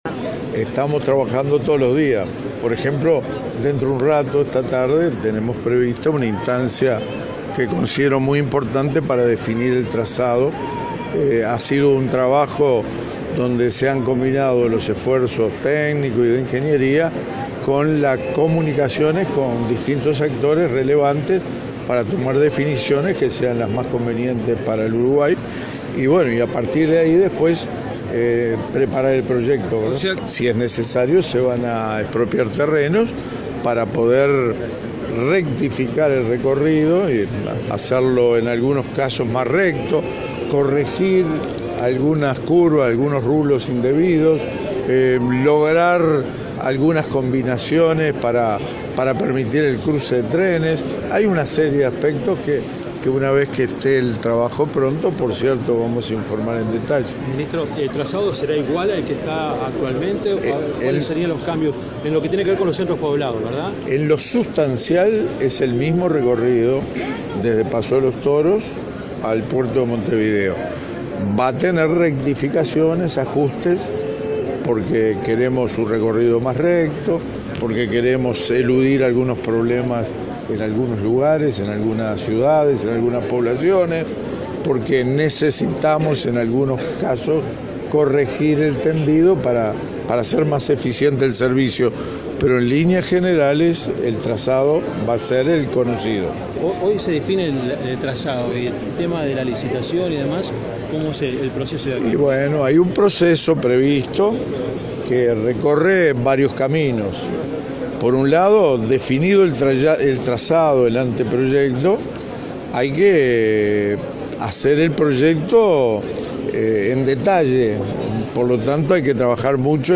El ministro de Transporte, Víctor Rossi, habló con la prensa de las obras ferroviarias necesarias para la proyectada construcción de una nueva planta de UPM. En ese sentido, dijo que en lo sustancial es el mismo recorrido desde Paso de los Toros al puerto de Montevideo, aunque tendrá algunas modificaciones y ajustes, ya que se necesita corregir el tendido para hacer más eficiente el servicio.